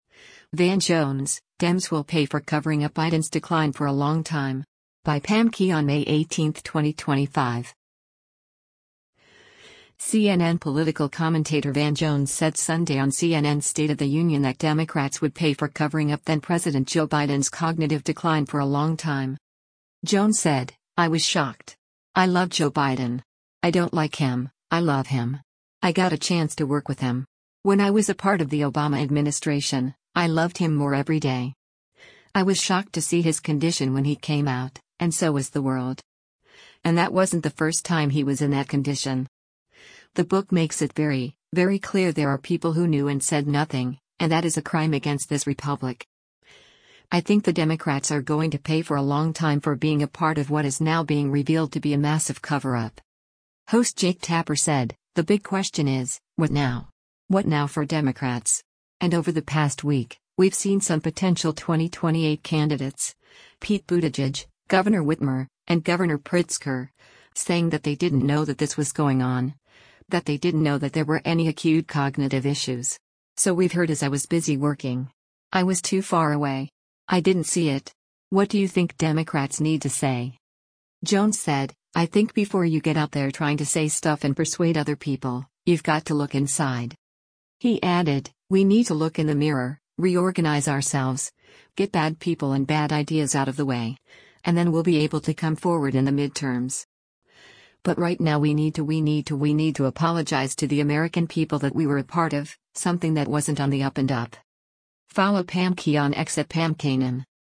CNN political commentator Van Jones said Sunday on CNN’s “State of the Union” that Democrats would pay for covering up then-President Joe Biden’s cognitive decline for a long time.